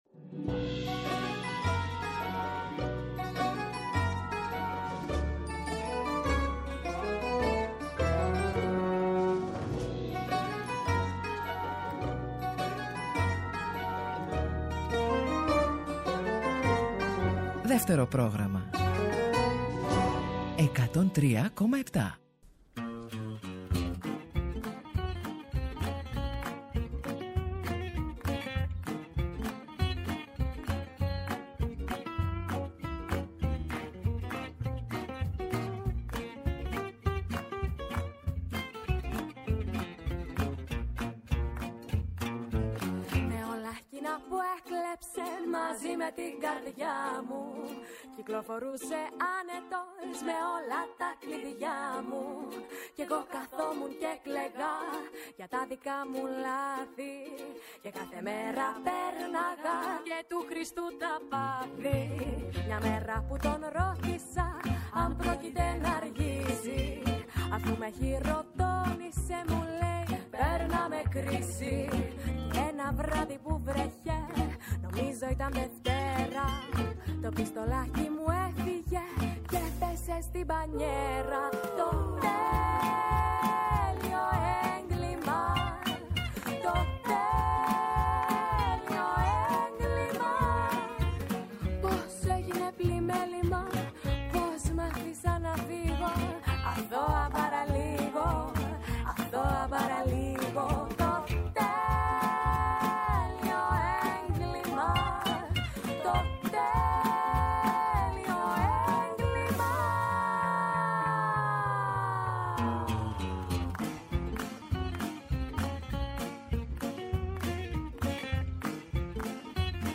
«Έχει η ζωή γυρίσματα» Μία δίωρη ραδιοφωνική περιπλάνηση, τα πρωινά του Σαββατοκύριακου.